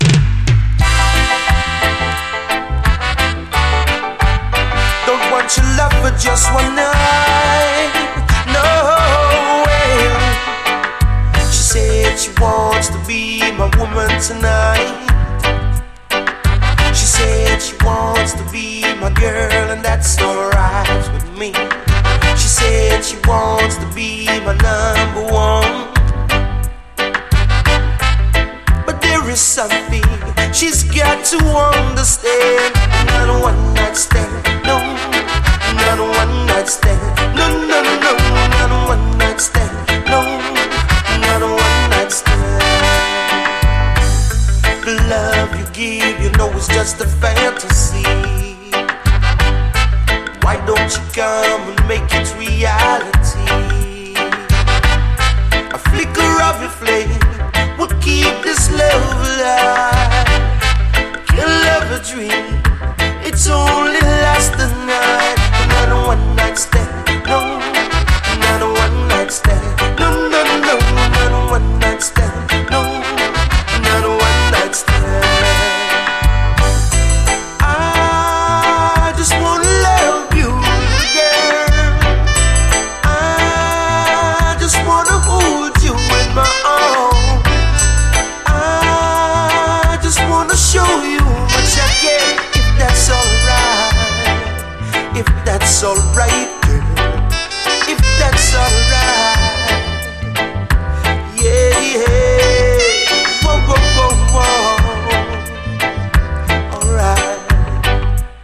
REGGAE
ブラス・アレンジが小気味よいメロウ＆ソウルフルな一曲！